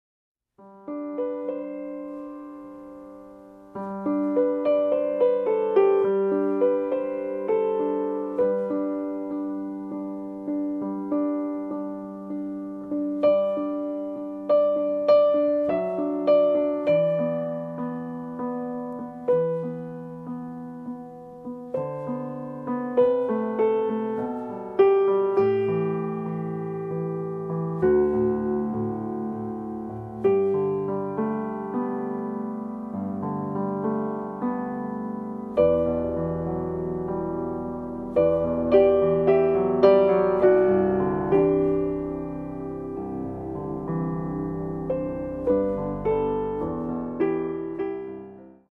Rich in melody and texture